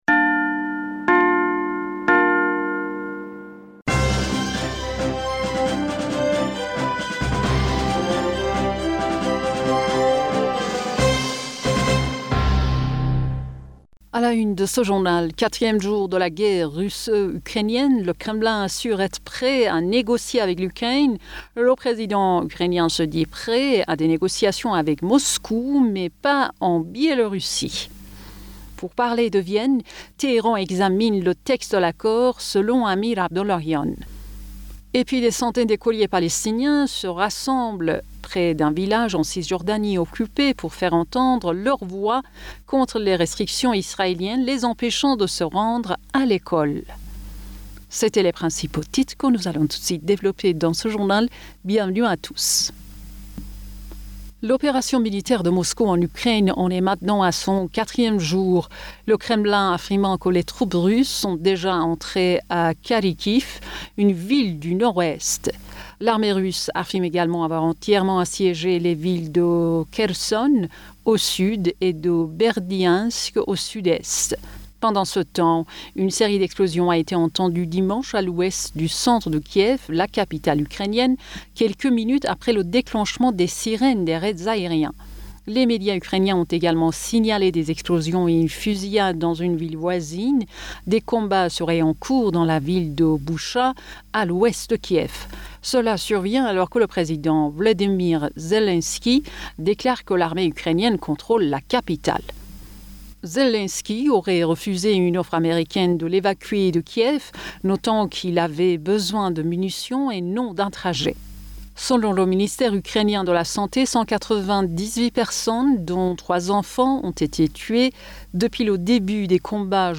Bulletin d'information Du 27 Fevrier 2022